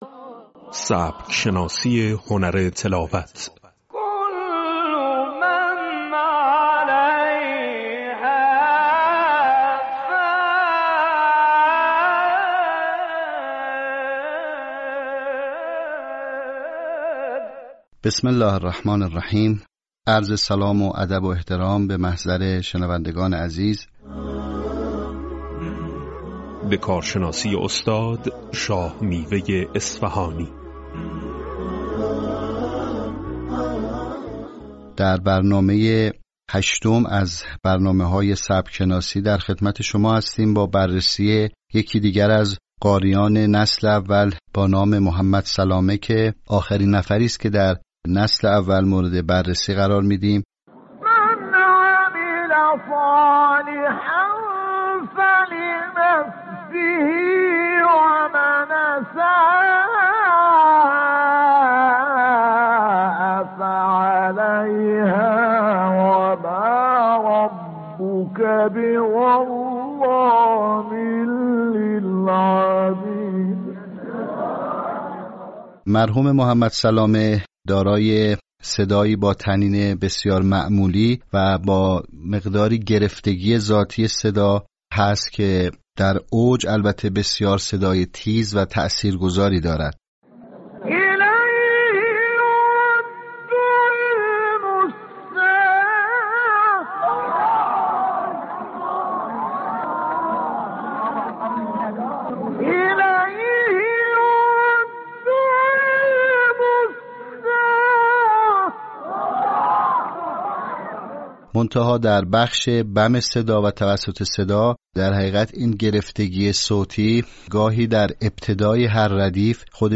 آموزش سبک شناسی در هنر تلاوت قرآن